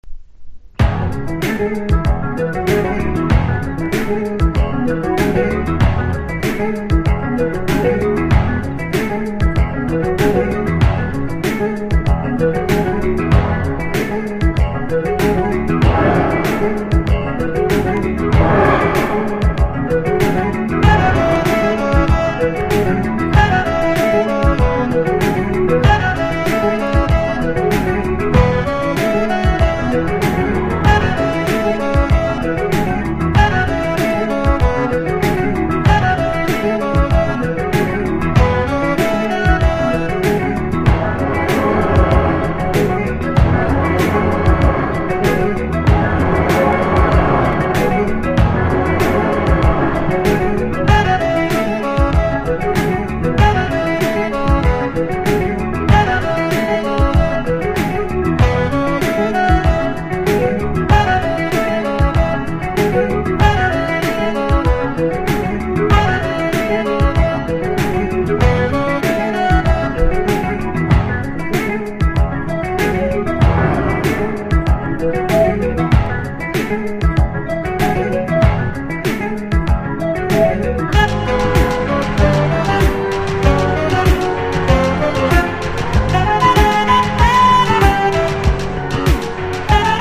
FUSION / JAZZ ROCK